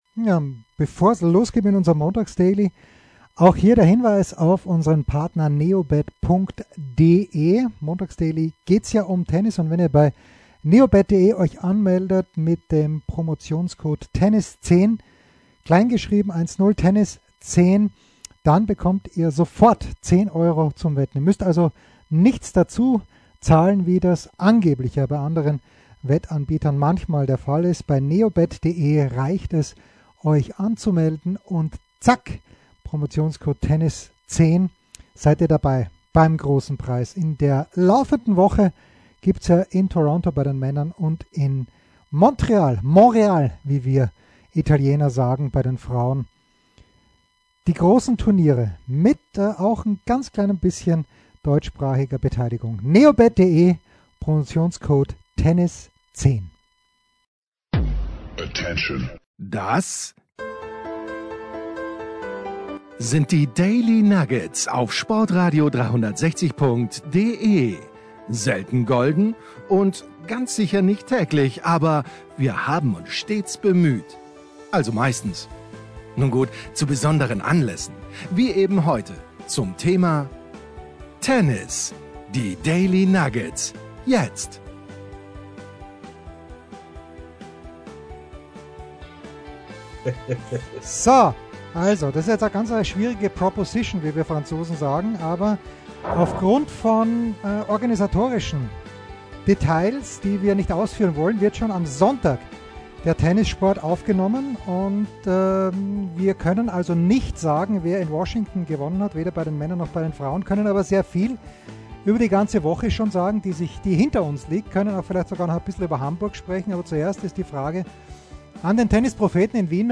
gepaart mit ein paar PK-Worten vom Finalisten Dominic Thiem.